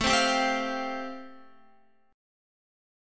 Listen to Am7#5 strummed